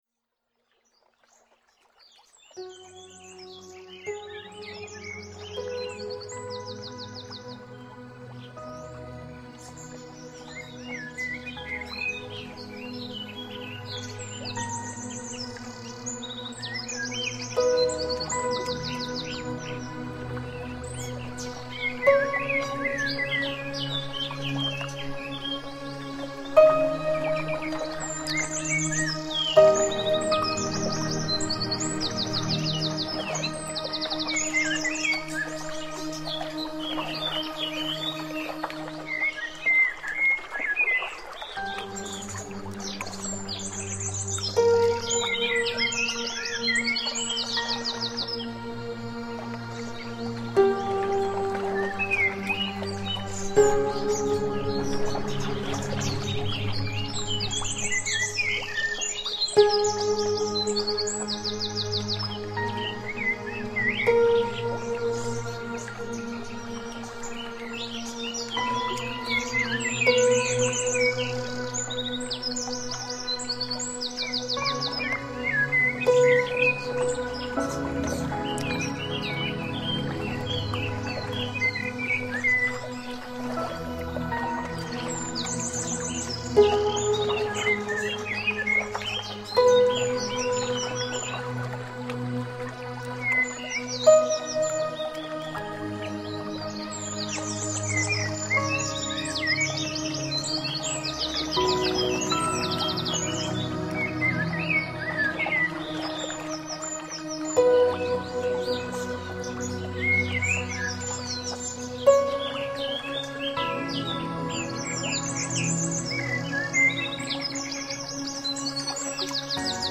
Медитация